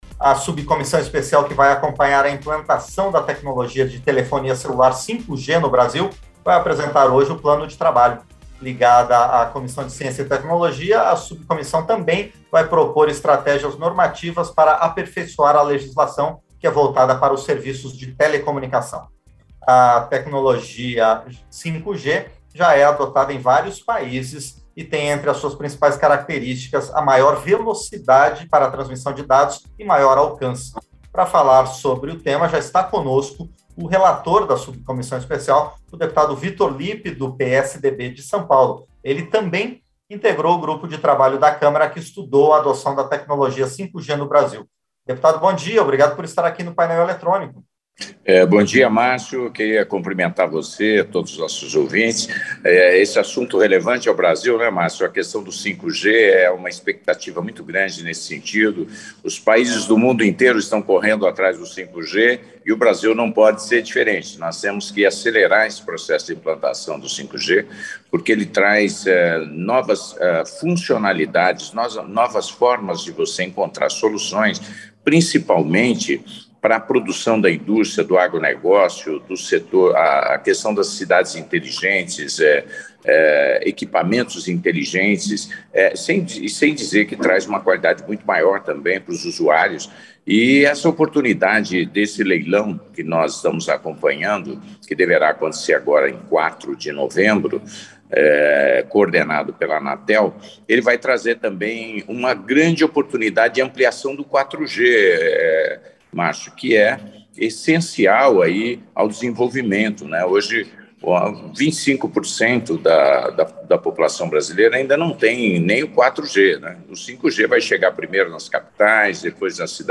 Entrevista - Dep. Vitor Lippi (PSDB-SP)